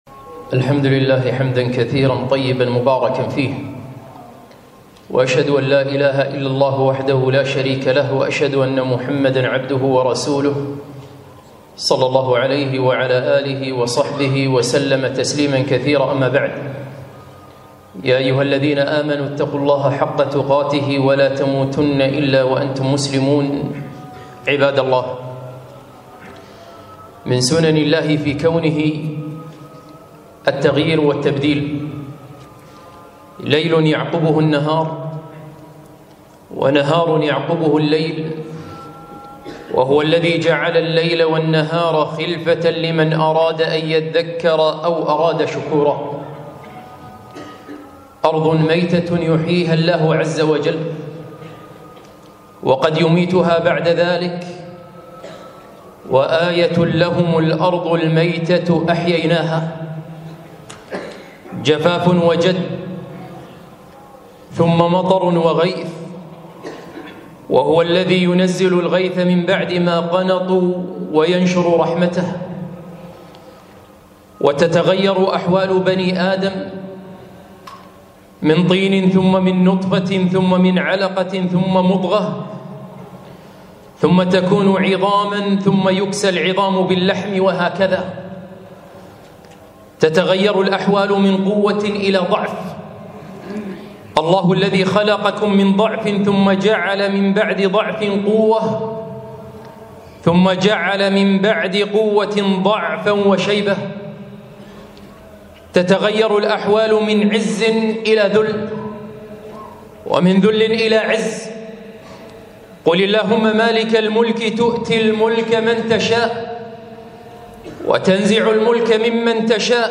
خطبة - أحداث سوريا وتقلب الأحوال